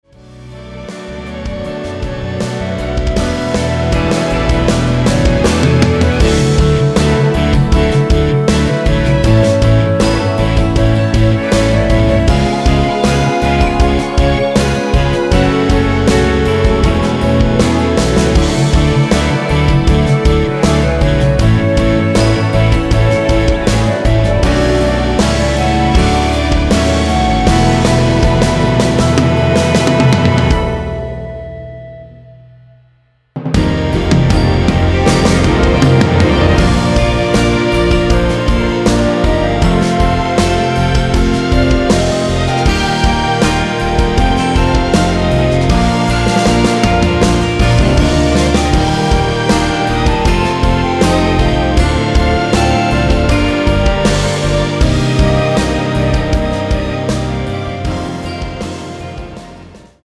노래방에서 음정올림 내림 누른 숫자와 같습니다.
멜로디 MR이라고 합니다.
앞부분30초, 뒷부분30초씩 편집해서 올려 드리고 있습니다.